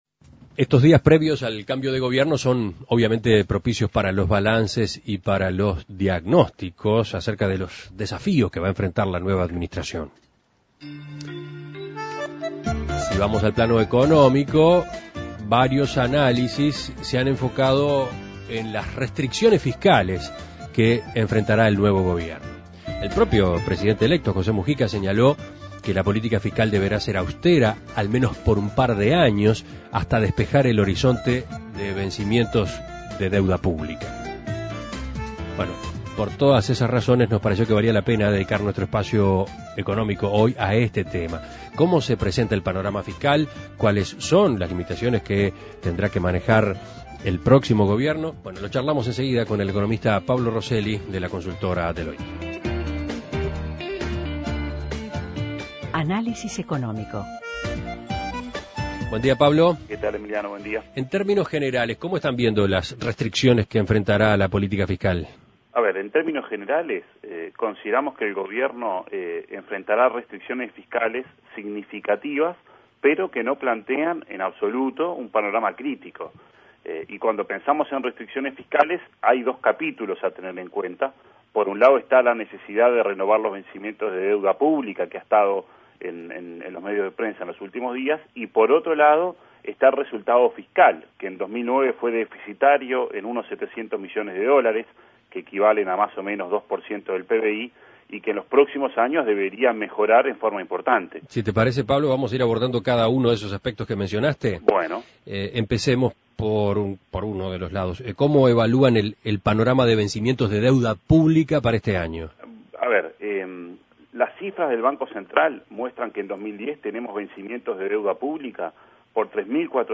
Análisis Económico ¿Cuáles son las restricciones fiscales que enfrentará la nueva administración?